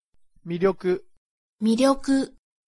Prononciation-de-miryoku.mp3